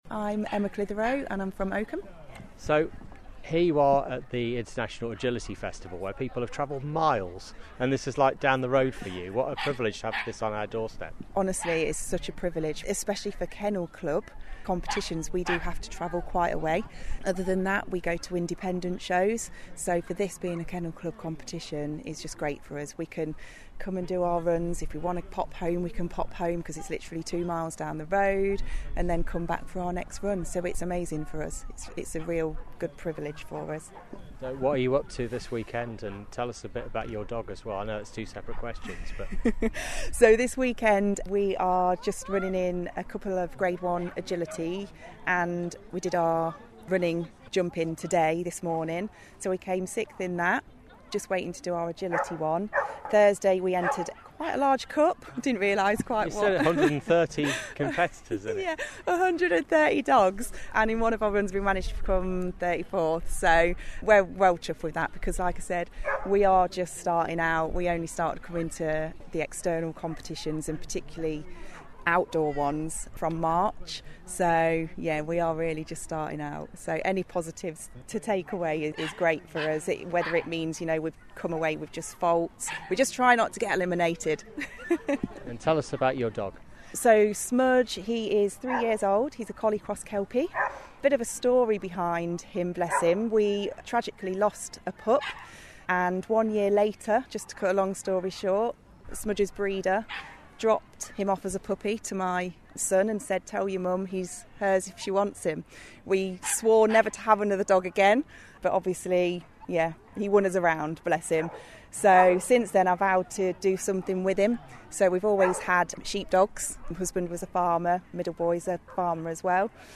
The event is on this weekend and open to the public.  There are over 2000 dogs at the Showground, with over 700 handlers from as far as Singapore.